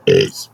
hey burp 5
burping cartoons effect funny game HEY movies musicals sound effect free sound royalty free Movies & TV